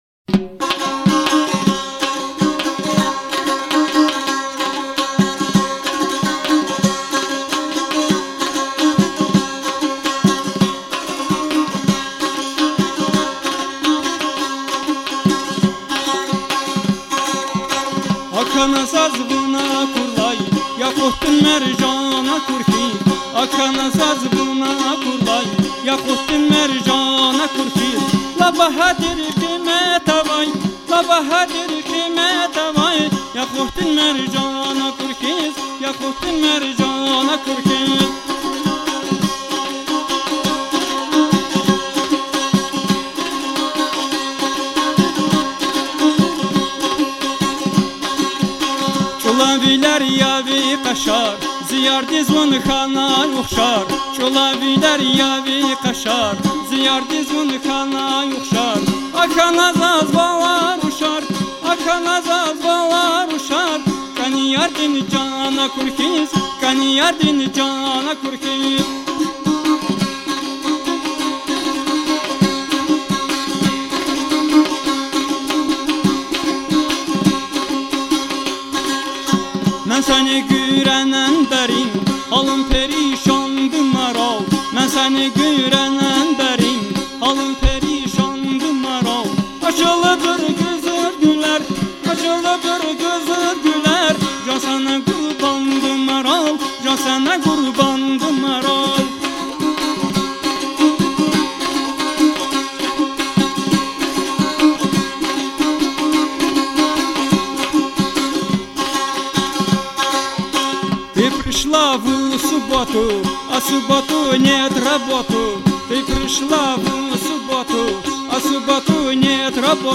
Экспедиция в Дагестан: "Путями древних Ашугов" (Ноябрь 2006 г.)
1 из треков записанных в экспедиции: